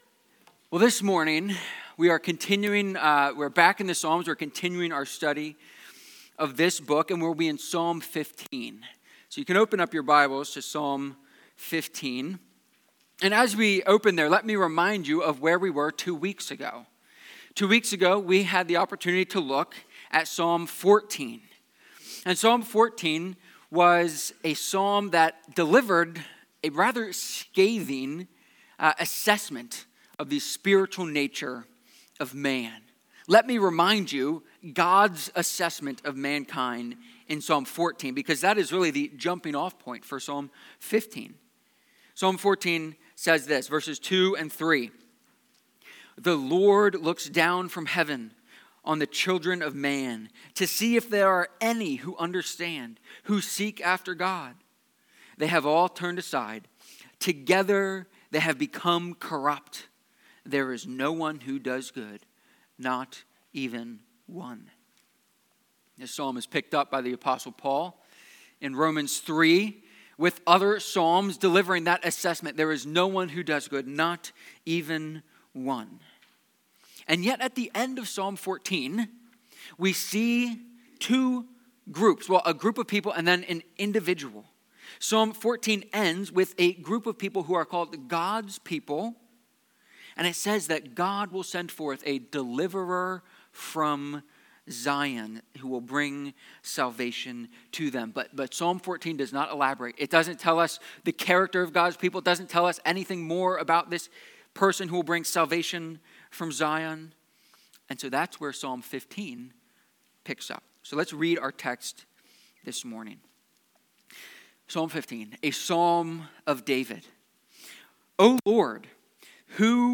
Psalm-15-sermon.mp3